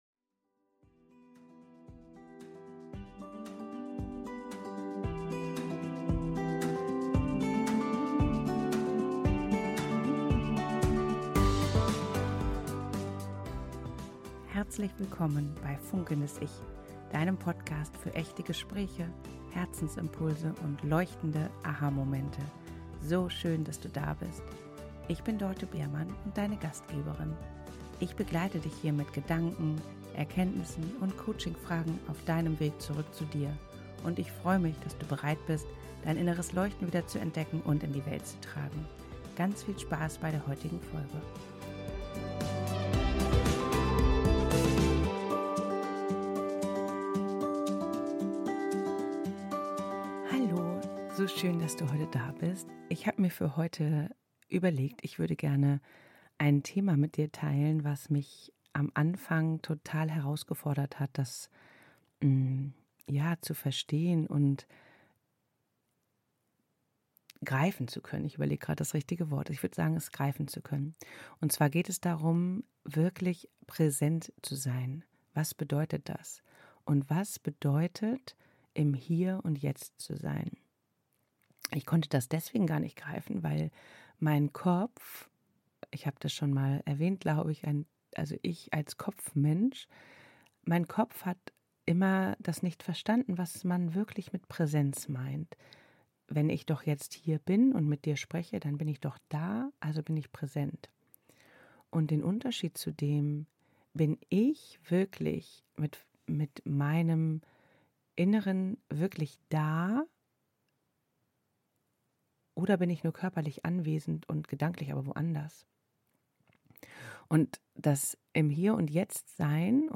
Wie oft rauschen wir durch den Tag, ohne wirklich zu spüren, wie es uns gerade geht! Ich habe lange gebraucht, um mir echte Jetzt-Momente zu erlauben – diese kleinen Augenblicke, in denen ich still werde, durchatme und wahrnehme, was gerade da ist. In dieser Solo-Folge erzähle...